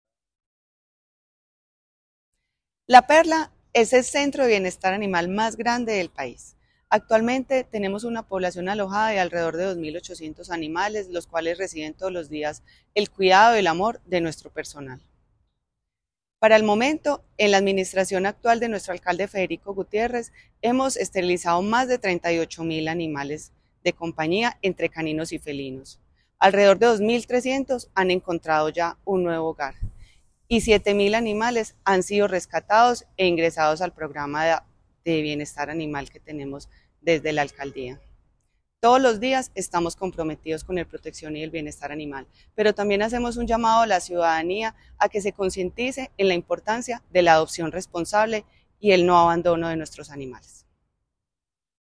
Declaraciones de la subsecretaria de Protección y Bienestar Animal, Elizabeth Coral
Declaraciones-de-la-subsecretaria-de-Proteccion-y-Bienestar-Animal-Elizabeth-Coral.mp3